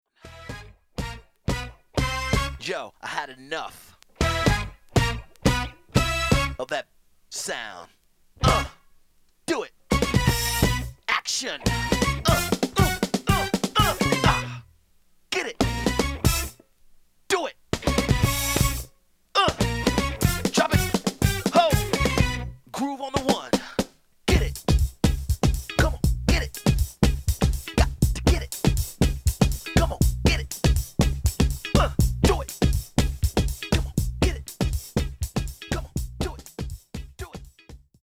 派手なイントロ、カットイン系でズバッといってください。